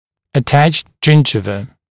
[ə’tæʧt ‘ʤɪnʤɪvə] [ʤɪn’ʤaɪvə][э’тэчт ‘джидживэ] [джин’джайвэ]прикрепленная десна